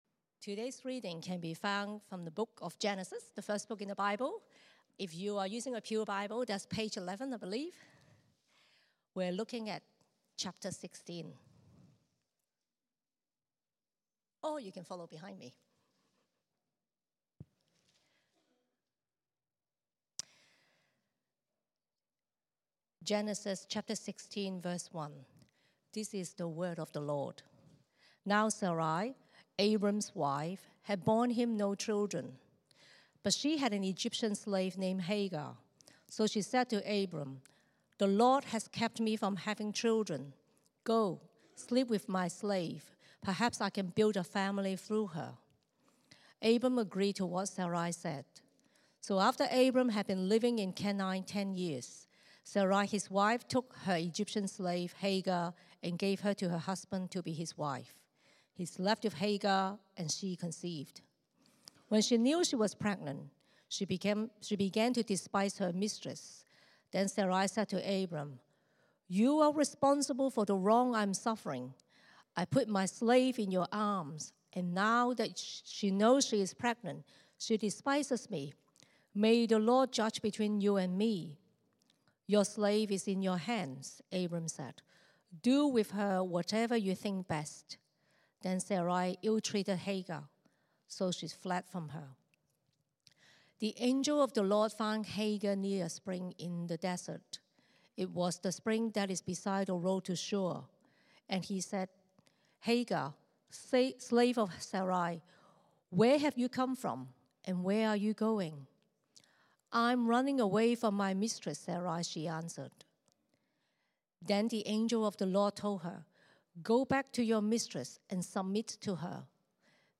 The sermon titled “Waiting is Not Wasting” examines the story of Abram, Sarai, and Hagar from Genesis 16. It discusses the challenges of waiting on God’s promises and how impatience led Abram and Sarai to act on their own, resulting in broken relationships and difficulties.